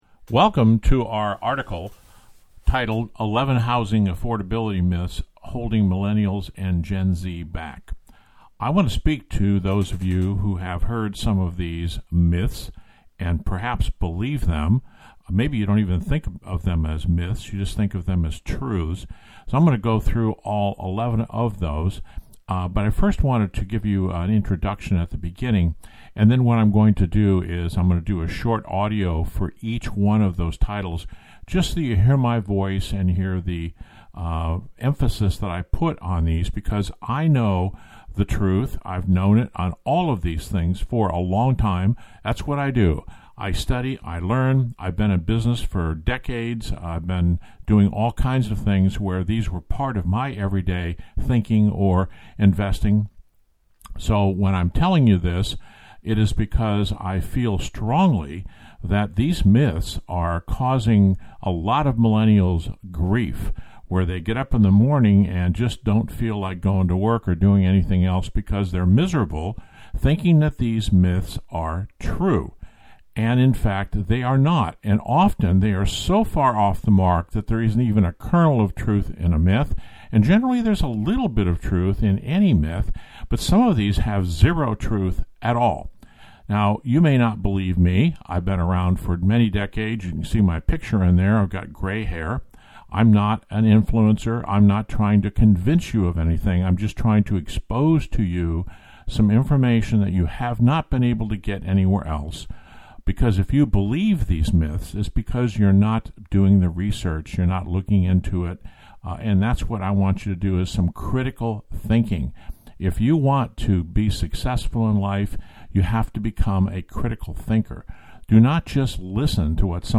Hear the author’s overview of the key housing affordability myths that affect Millennials and Gen Z — in your car, on the go, or as you read.